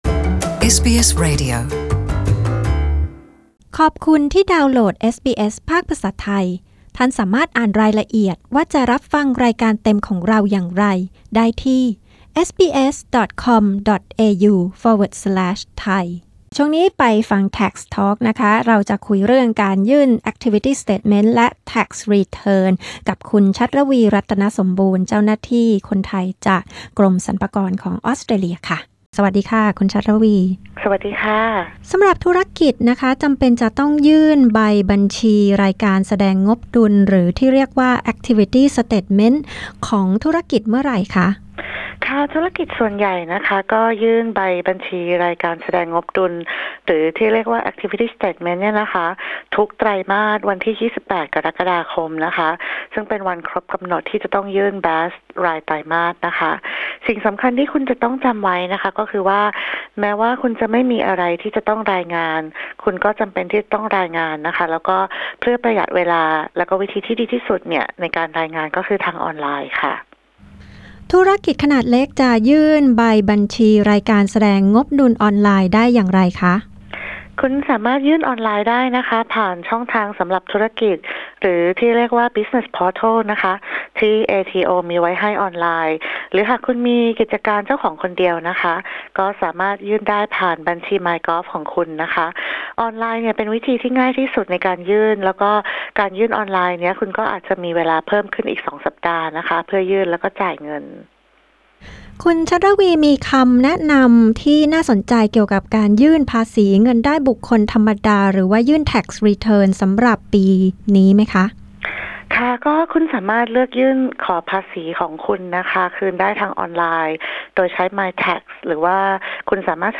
เจ้าหน้าที่กรมสรรพากรออสเตรเลีย อธิบายการยื่น BAS และการยื่นภาษีเงินได้บุคคลธรรมดา และแนะนำข้อมูลที่จะช่วยให้คุณยื่น Tax Return ได้อย่างมีประสิทธิภาพ